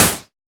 RDM_TapeA_SY1-Snr02.wav